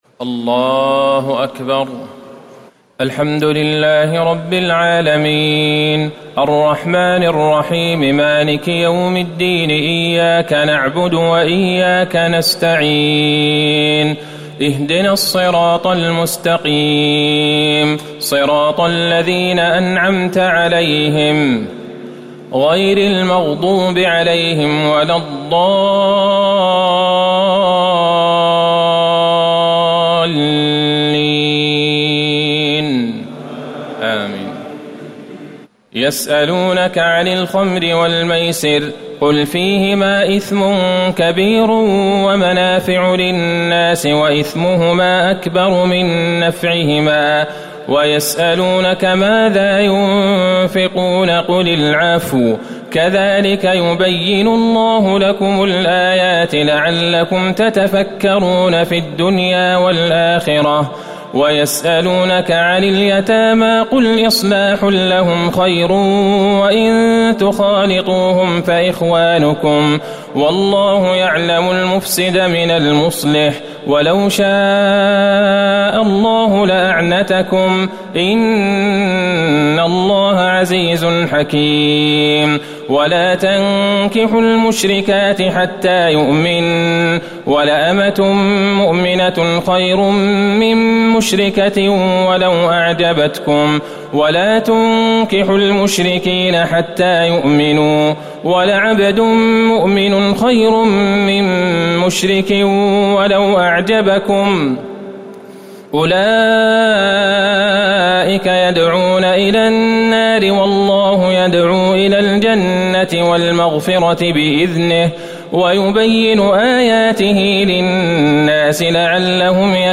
تهجد ليلة 22 رمضان 1439هـ من سورة البقرة (219-253) Tahajjud 22 st night Ramadan 1439H from Surah Al-Baqara > تراويح الحرم النبوي عام 1439 🕌 > التراويح - تلاوات الحرمين